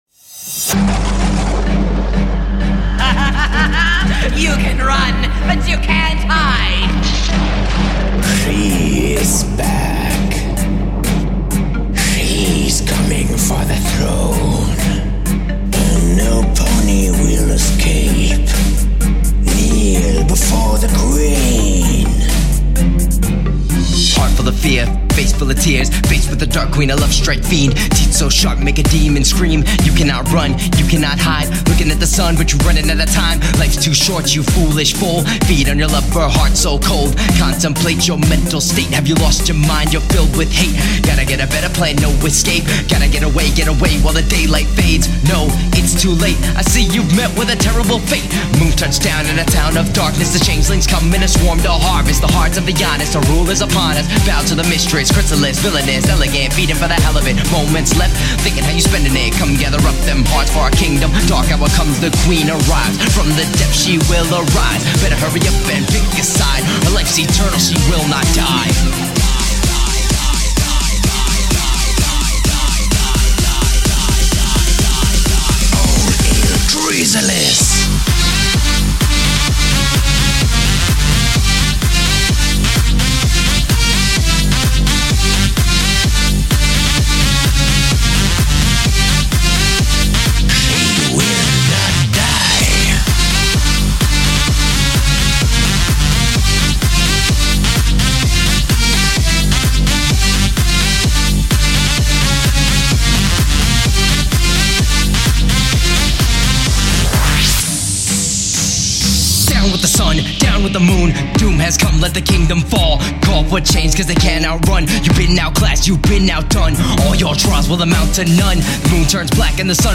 Expect some french rap in it!